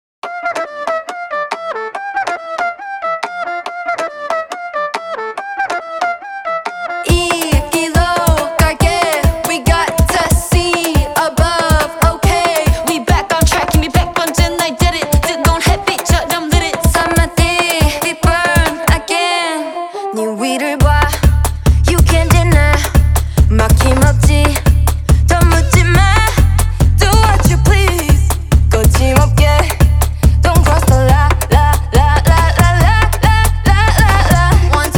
Скачать припев, мелодию нарезки
K-Pop Pop
2025-04-28 Жанр: Поп музыка Длительность